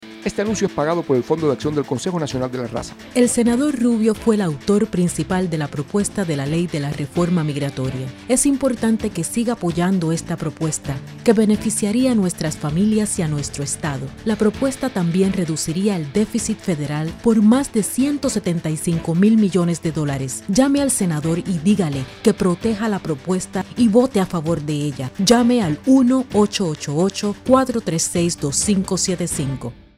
Sprechprobe: Sonstiges (Muttersprache):
Girl next door,fun,warm,sophisticated,sexy